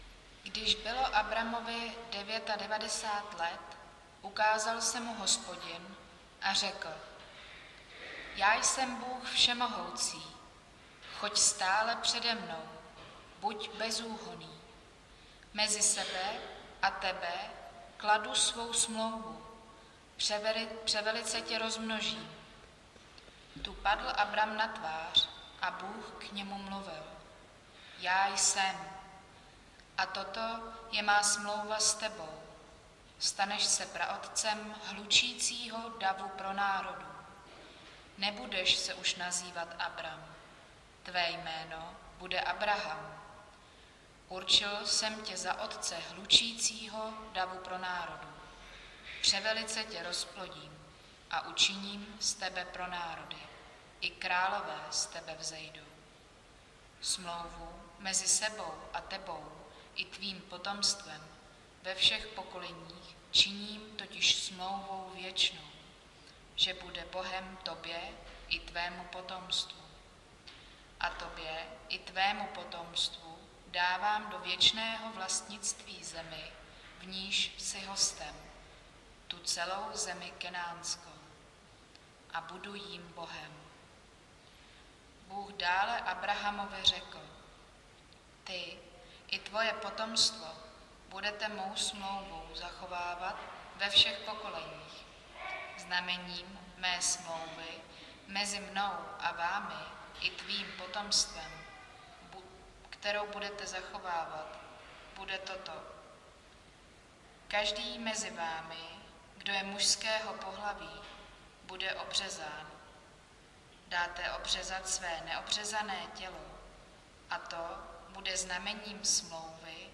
Nedělní kázání – 23.10.2022 Proměněný smích